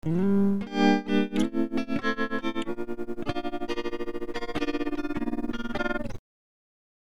Tremolo is a periodic variation in the volume of a sound.
Tremolo with a linearly increasing frequency ranging from 0 Hz to 40 Hz, a minimum value of 0, and a maximum value of 1